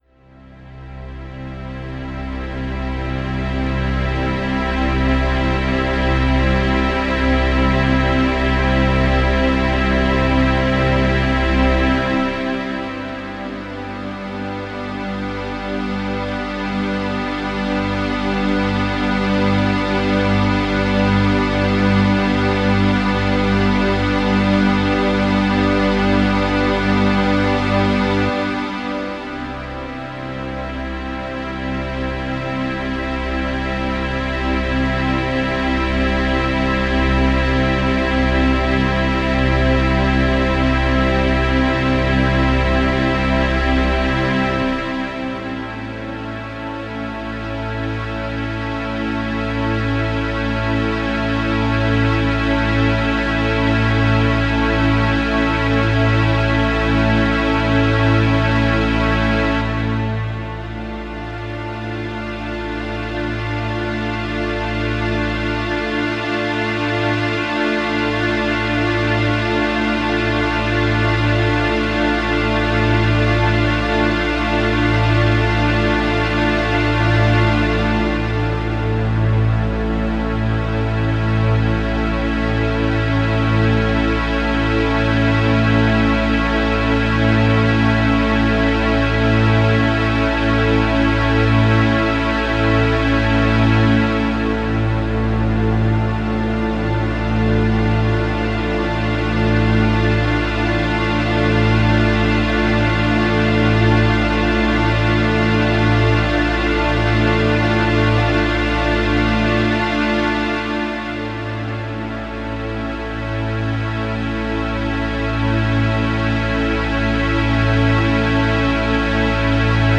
Die Musik führt uns zur inneren Harmonie.
wohltuend harmonisierend